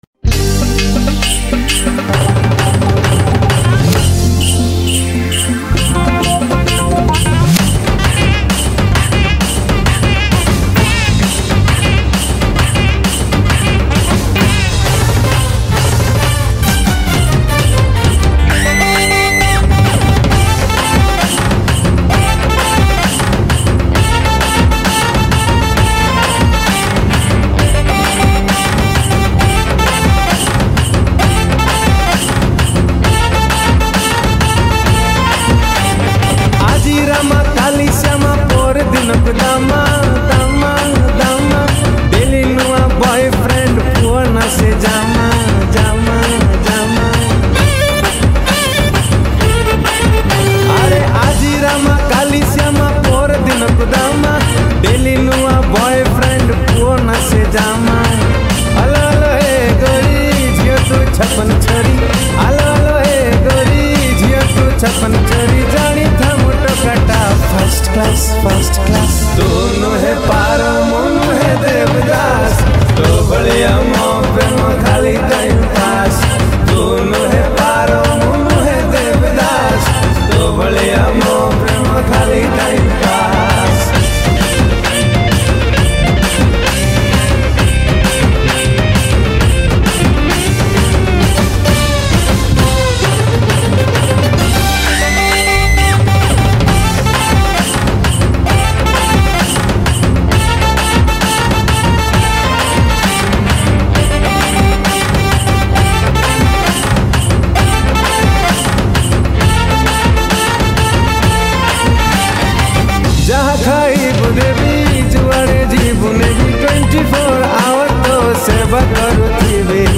Odia Dance Song